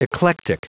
Prononciation du mot eclectic en anglais (fichier audio)
Prononciation du mot : eclectic